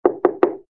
GUI_knock_2.ogg